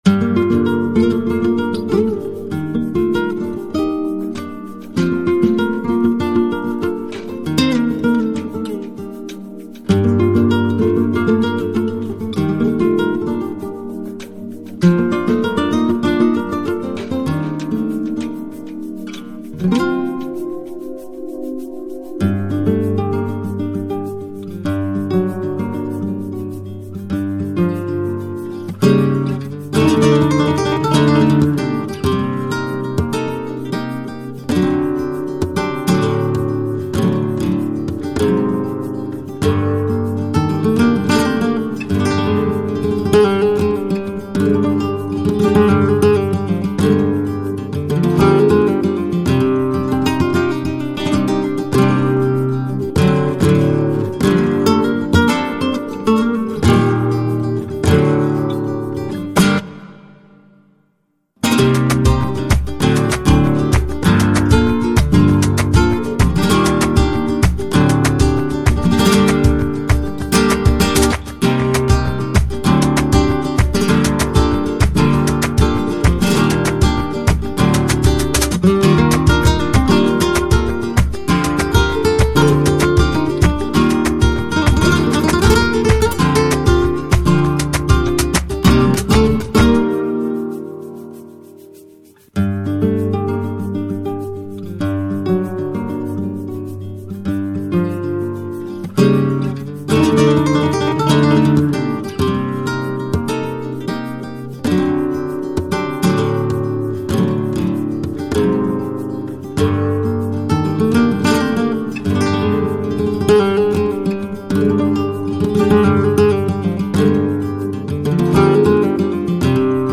SPANISHGUITARMASTER.mp3